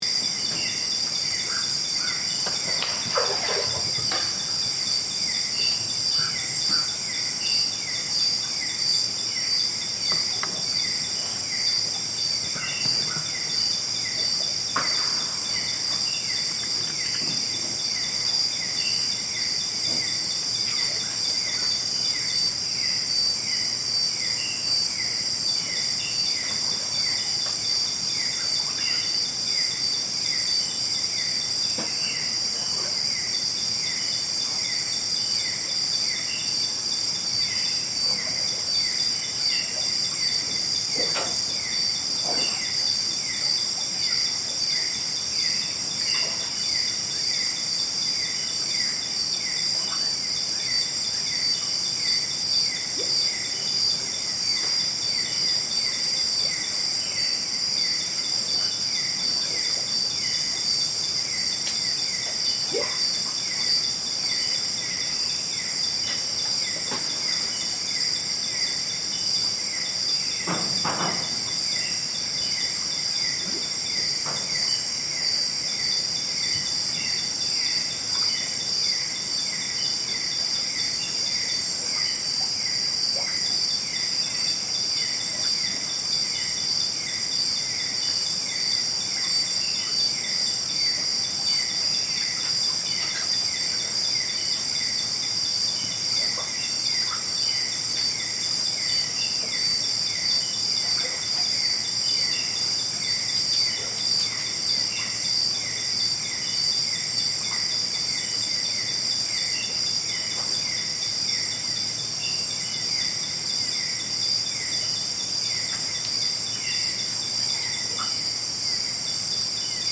Nighttime In Sabah rainforest
Estimated to be more than 130 million years old, the ancient rainforest of Sabah comes alive in sound as night descends. The air vibrates with the calls of nocturnal creatures, forming a chorus in the darkness. From the steady hum of countless insects to the distant cries of animals hidden, the forest pulses with life.